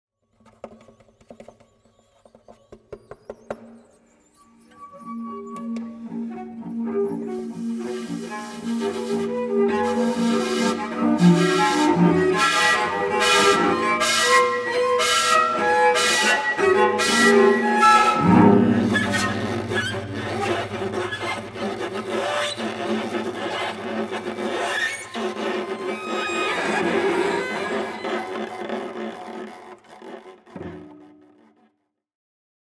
at bernard haitink hall of the conservatory of amsterdam
clarinet and shakuhachi
cello